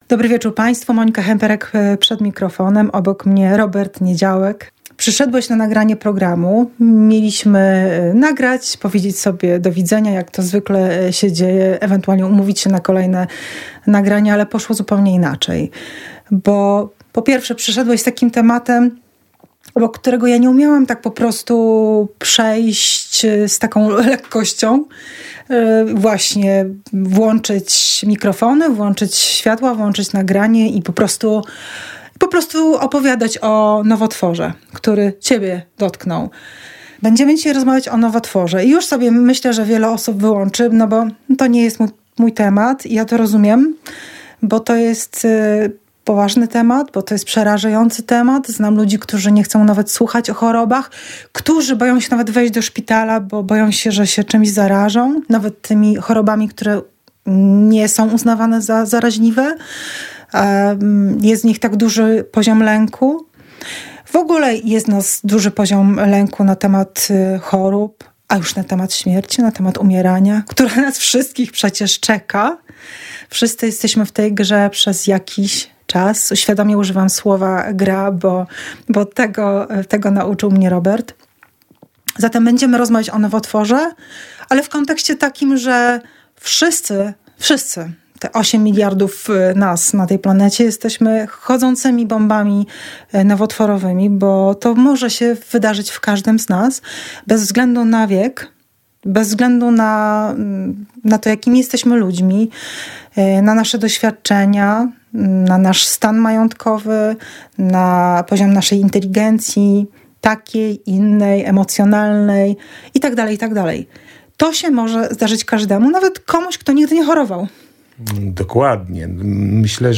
To rozmowa o radości i pięknie życia.